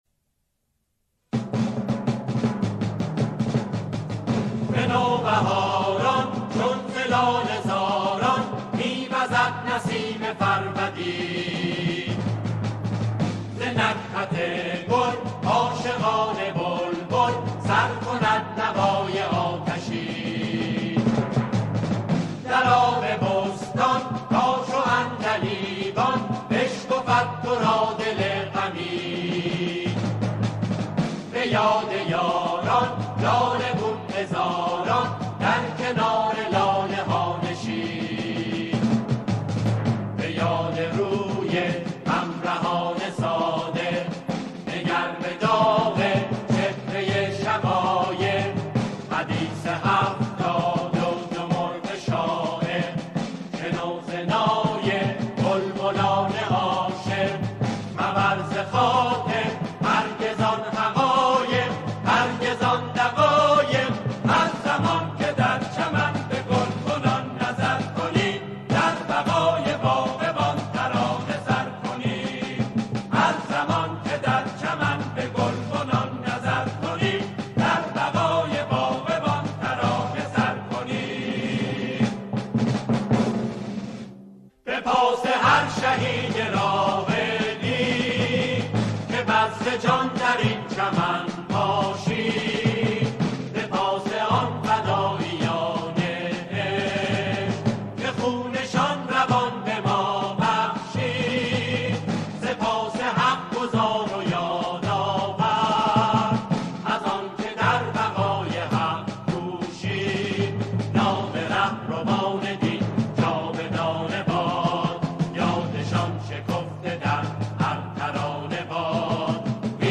سرودهای دهه فجر
آنها در این قطعه، شعری را درباره دهه فجر همخوانی می‌کنند.